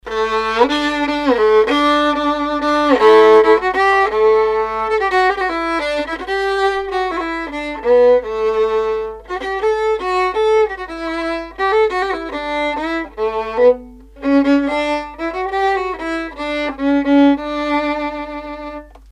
The tone of this fiddle is now superb! A very good G string.
Good balance between strings, a nice breathy A string, and it has no noticeable vices anywhere, other than the repair.
Here's what it sounds like: showing G and D strings,
This fiddle has an enormous G-string sound that's easy to draw out.